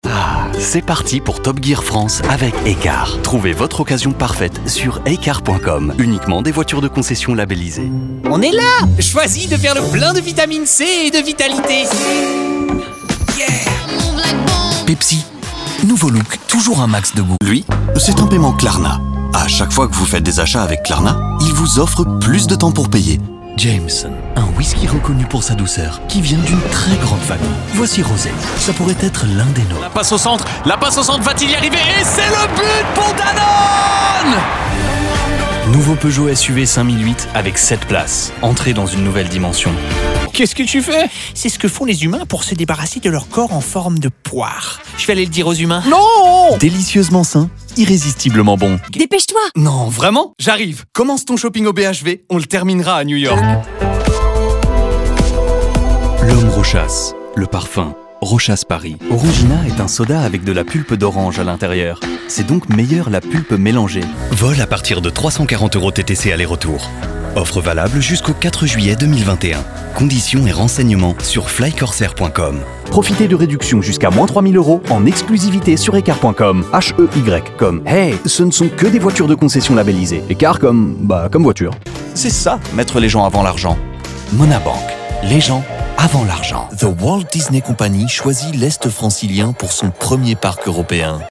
FRENCH / BRITISH ENGLISH / AMERICAN ENGLISH
FRENCH - COMMERICAL